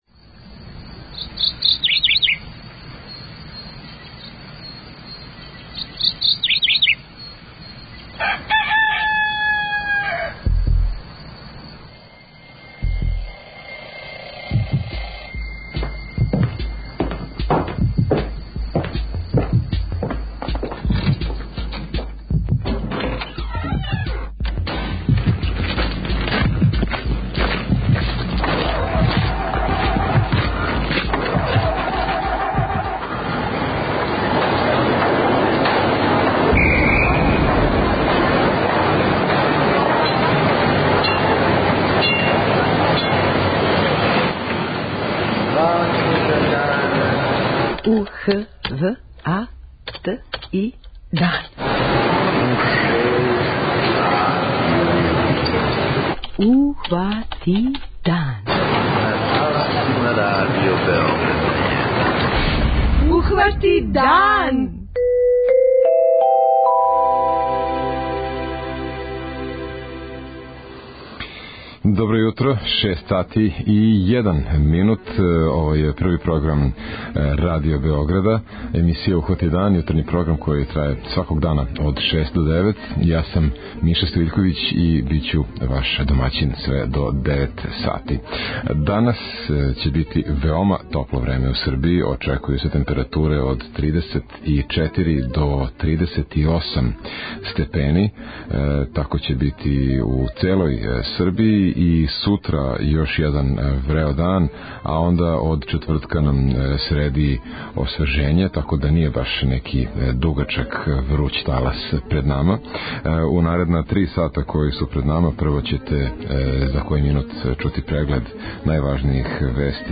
- разговор с младим инжењерима који су пројектовали електрични трактор "Идворски", а пре неколико дана вратили су се с једног великог међународног такмичења у Енглеској